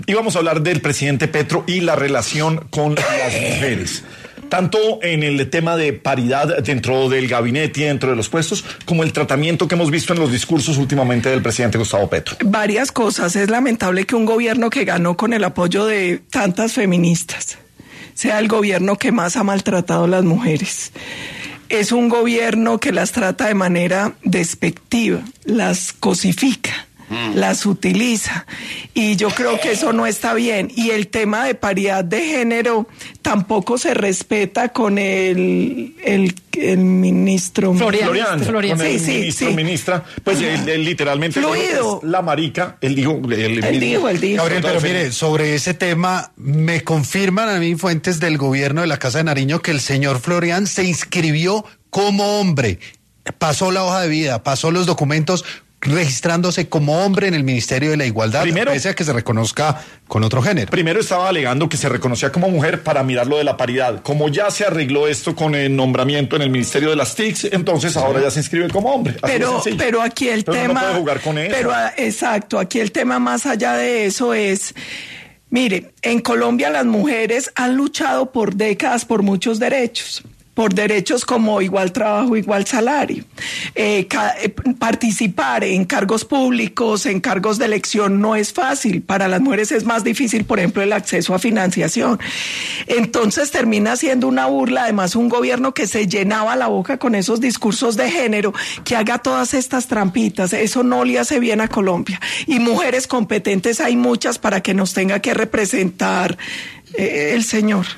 Paola Holguín estuvo en ‘Sin Anestesia’ de ‘La Luciérnaga’, para hablar de los últimos escándalos que afectan a Gustavo Petro, en relación con la paridad de género dentro del gabinete, como en el tratamiento de los últimos discursos.